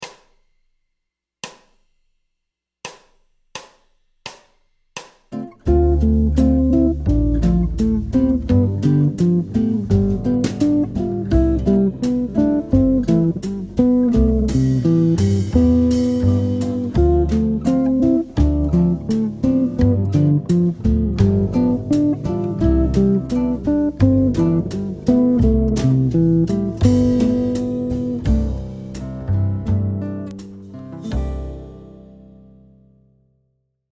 Motif Jazz D1 : Arpège 7 ascendant
Phrase 10 – Cadence ii . V7 . I en Majeur
Arpèges ascendants après des arpèges ‘Honeysuckle’ de base ou substitutifs.
Phrase-10-ii-V7-I-en-C-Maj.mp3